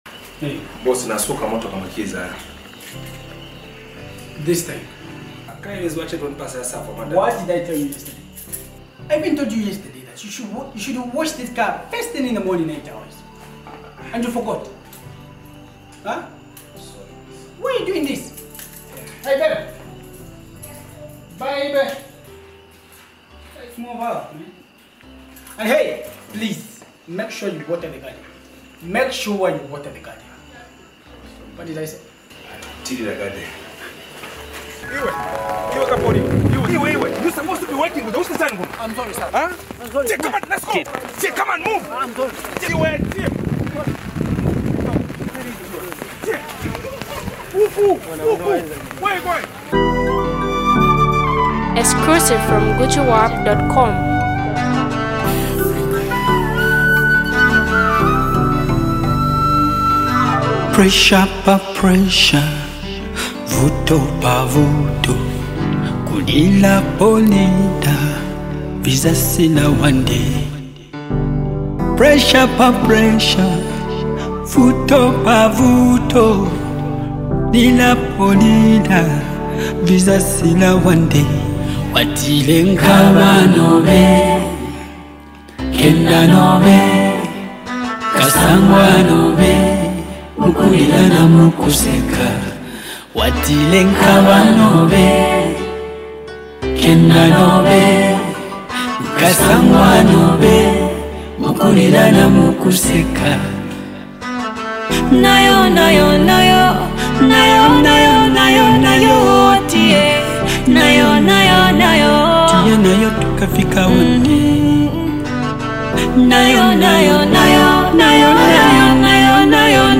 Renowned for his soulful sound and irresistible beats
mesmerizing vocals weaving seamlessly through the track